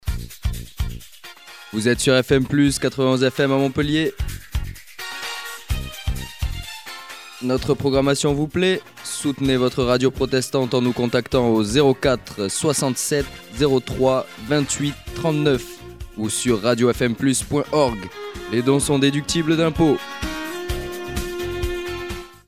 [Spot] Soutenez FM-PLUS 91 FM à Montpellier
gingle-dons.mp3